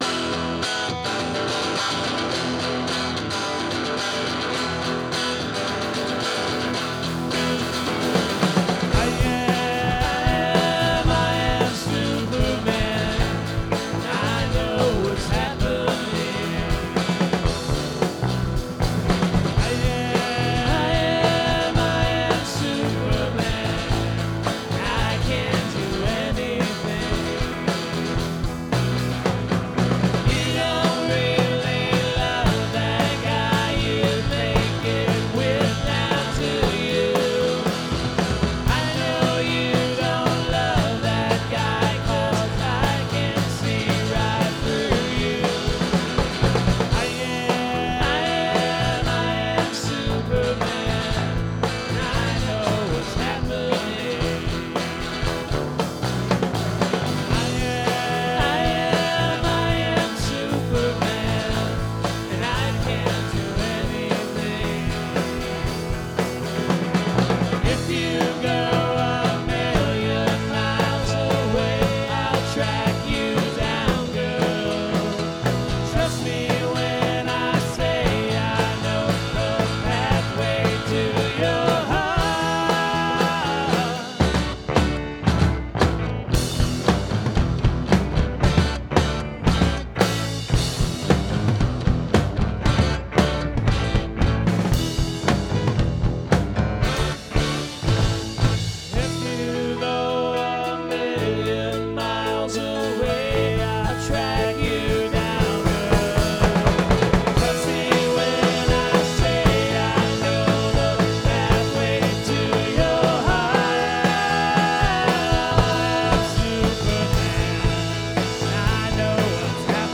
Recorded 5/24/17 at Martyrs', Chicago, IL